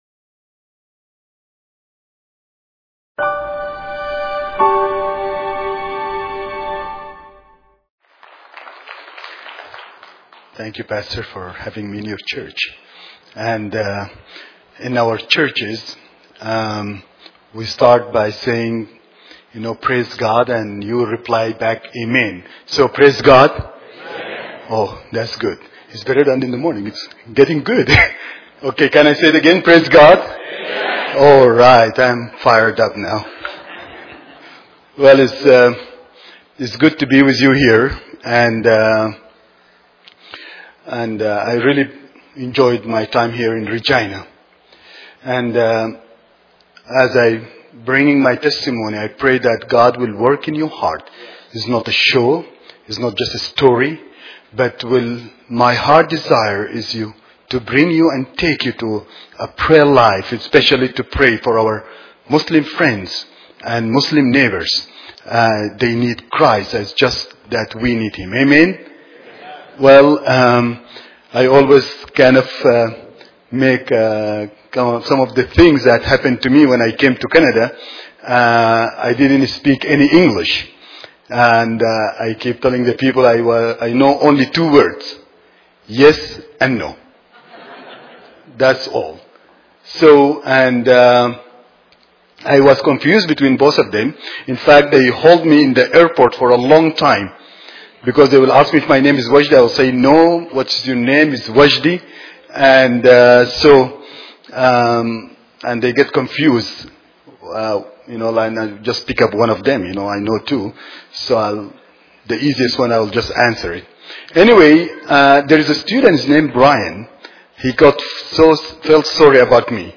This sermon is a powerful testimony of a former Muslim who converted to Christianity and faced persecution, imprisonment, and the threat of execution for his faith. It highlights the journey of surrendering to Jesus, experiencing God's love and forgiveness, and the importance of praying for Muslims.